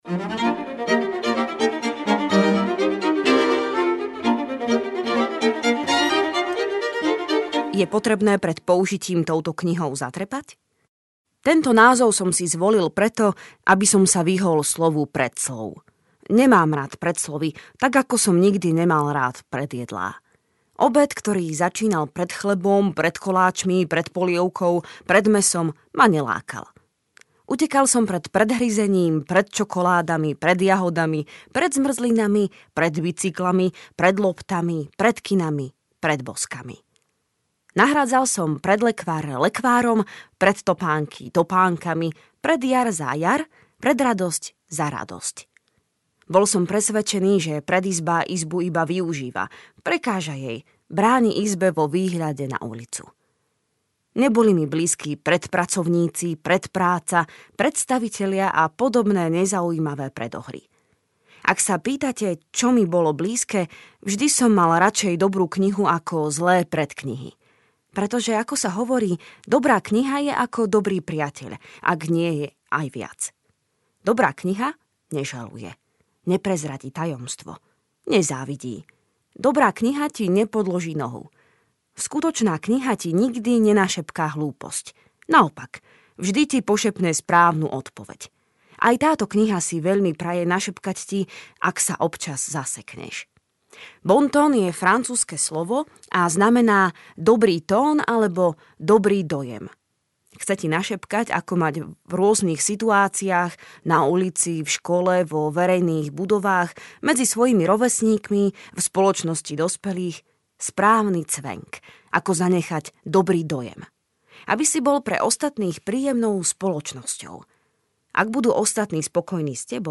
Ukázka z knihy
bonton-pre-deti-audiokniha